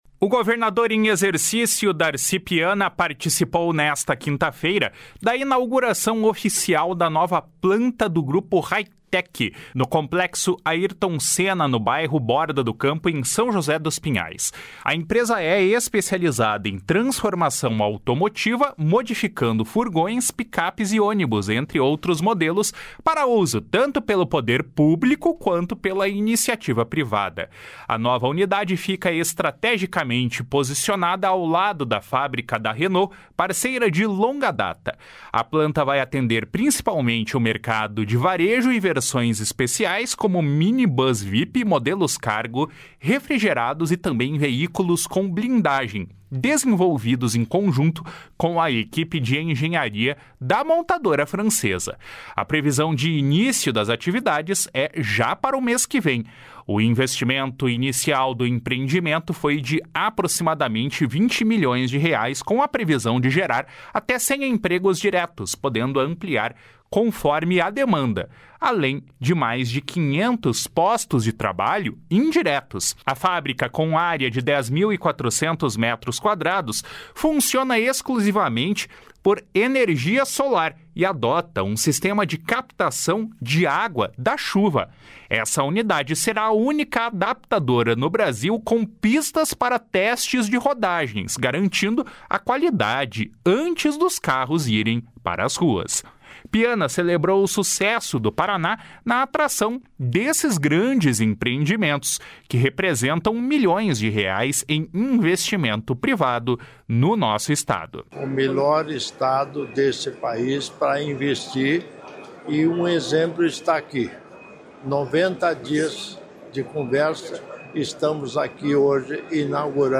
Piana celebrou o sucesso do Paraná na atração de grandes empreendimentos, que representam milhões de reais em investimento privado no Estado. // SONORA DARCI PIANA //